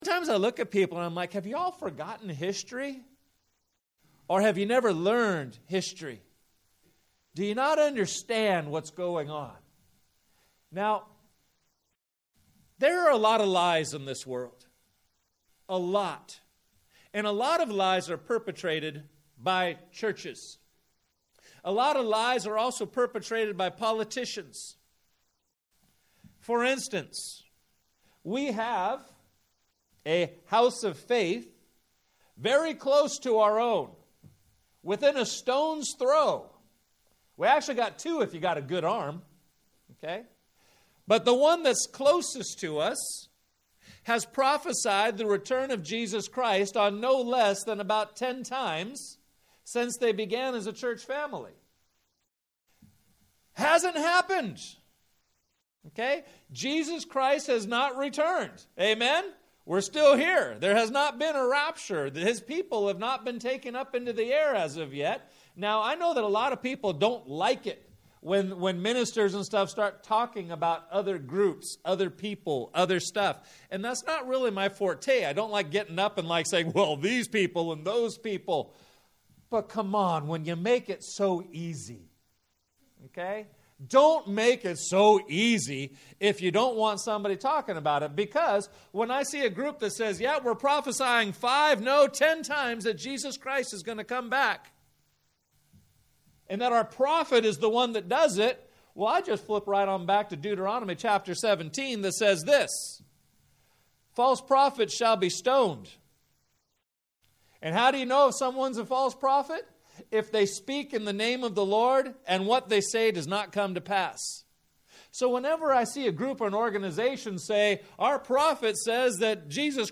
Sermons - First Baptist Church Solvang